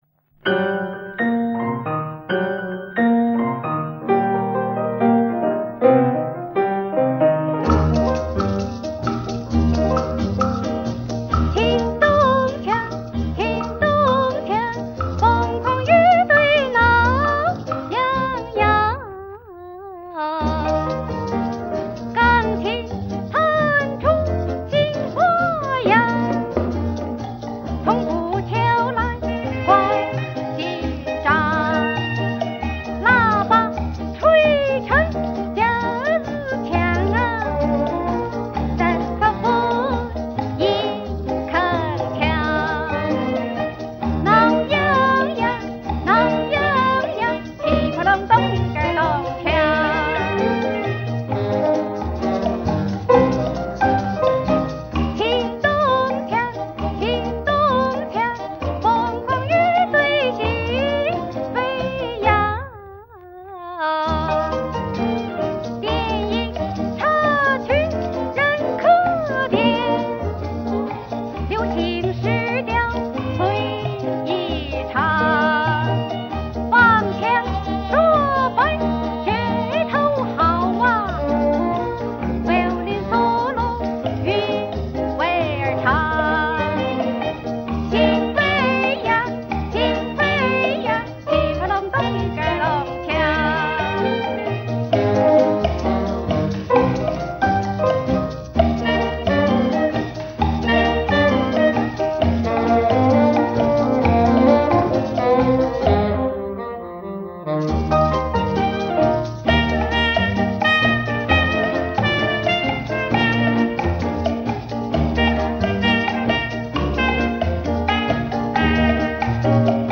如常，质方面，应考虑到歌曲都是几十年前的录音，调整一